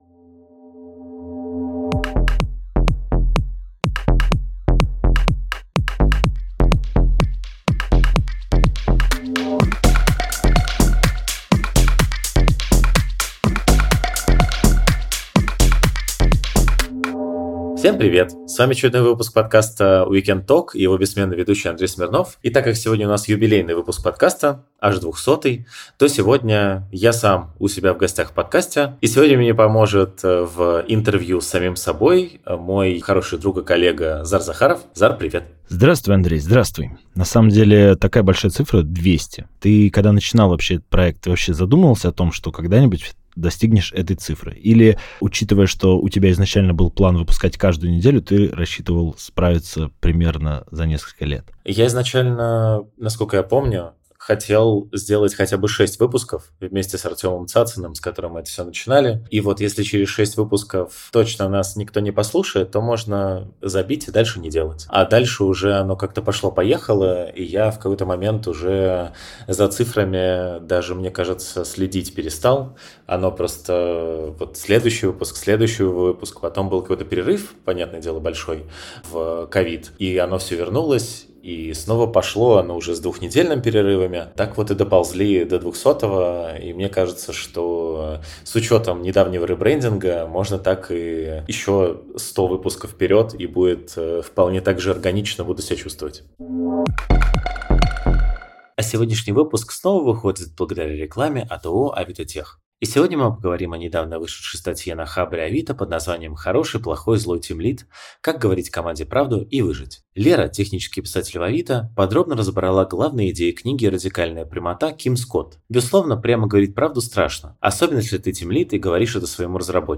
Самые честные интервью с известными людьми из мира web-разработки.